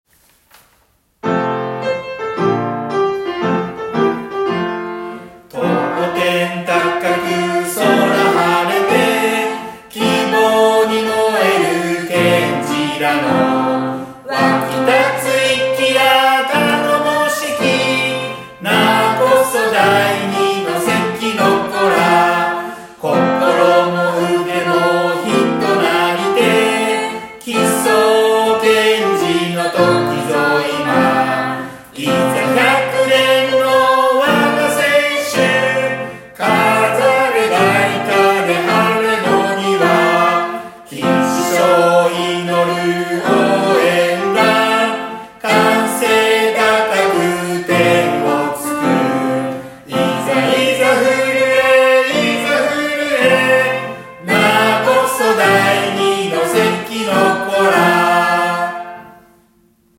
また、PTA本部役員さんのご家族にもご協力いただき、楽譜の確認をし、音楽室で録音をしました。